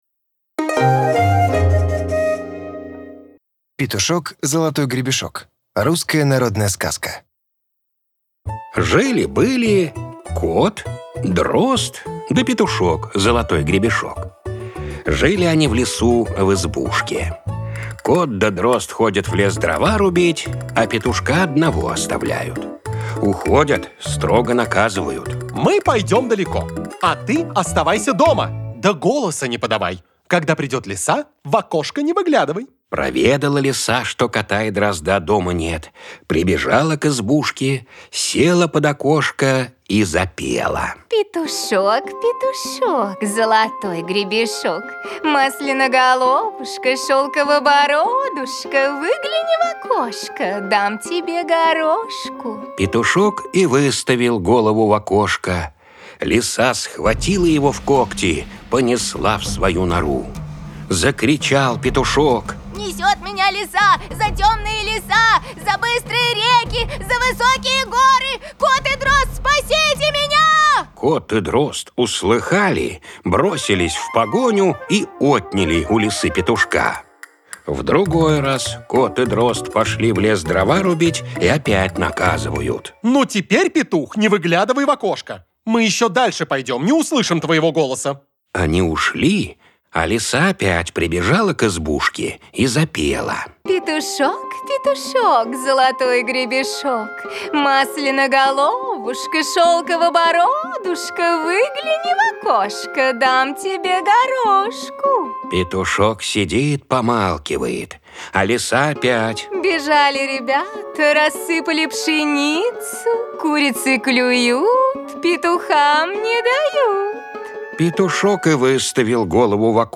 Жанр: fairytales